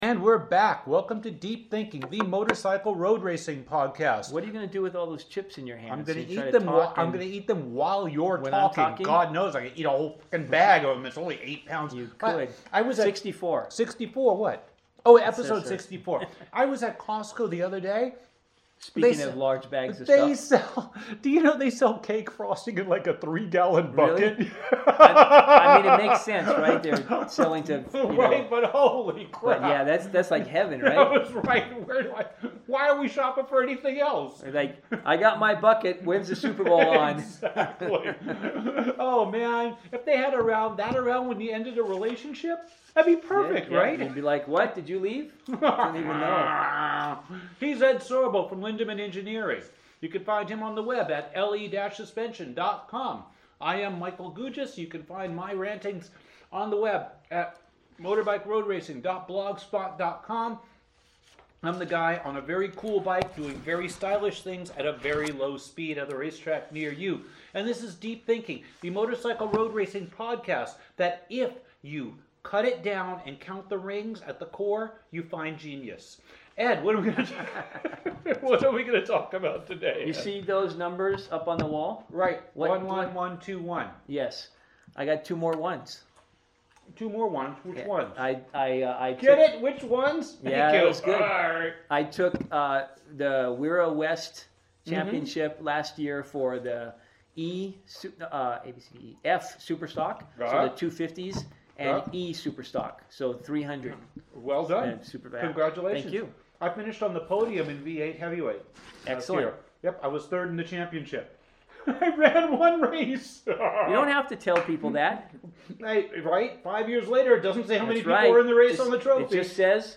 tries to talk and eat at the same time, but succeeds only in cracking himself up